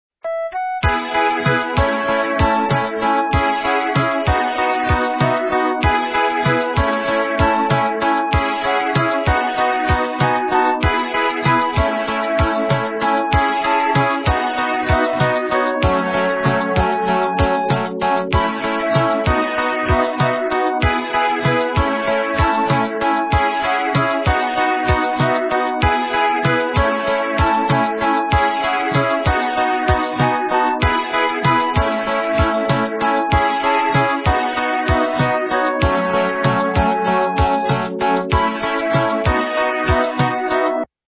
западная эстрада
полифоническую мелодию